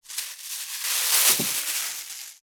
2025年3月1日 / 最終更新日時 : 2025年3月1日 cross 効果音
597コンビニ袋,ゴミ袋,スーパーの袋,袋,買い出しの音,ゴミ出しの音,袋を運ぶ音,